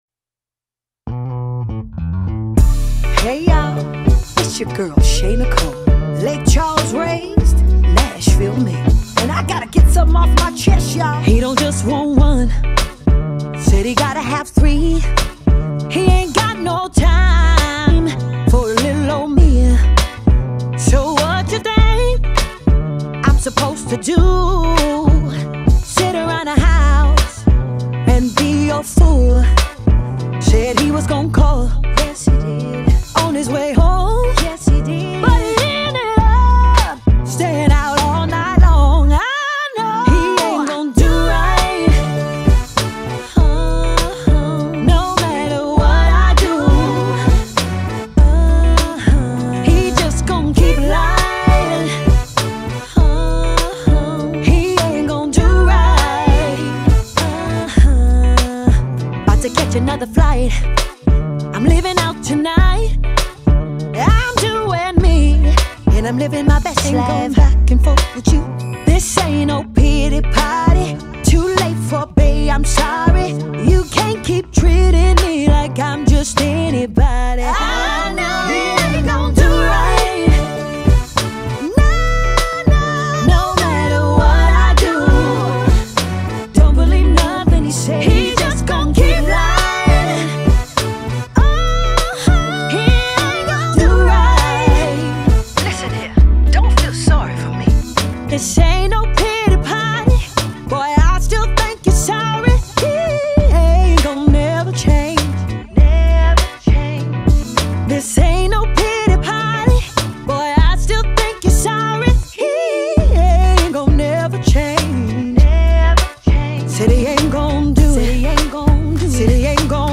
Genre: Southern Soul.